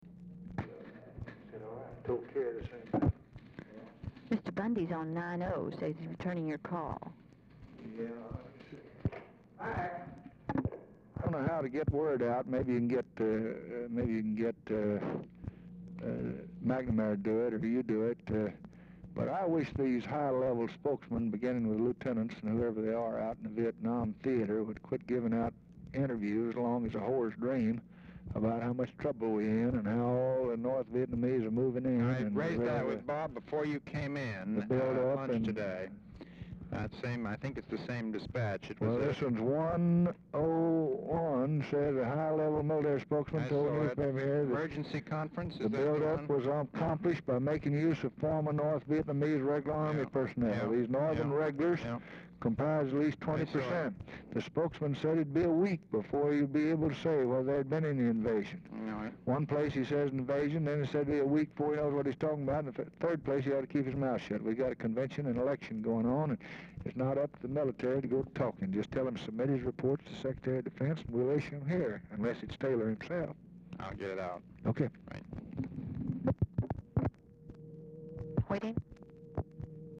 Oval Office or unknown location
Telephone conversation
Dictation belt